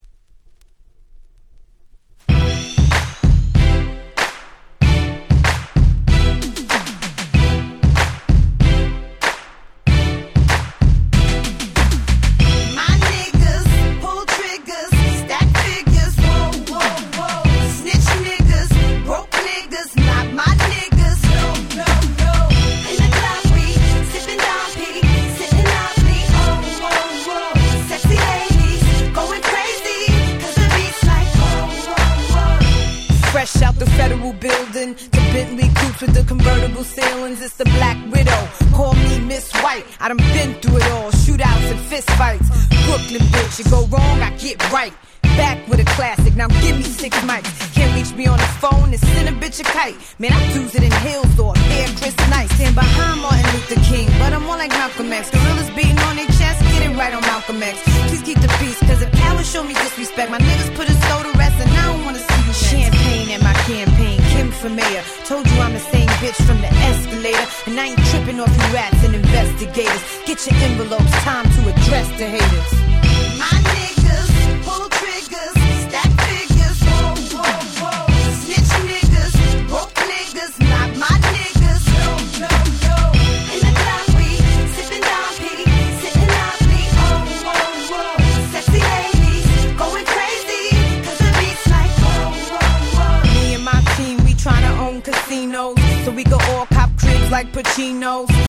05' Big Hit Hip Hop !!
キャッチーなBeatとサビも相まってここ日本のClubでも当時はかかりまくっておりました！！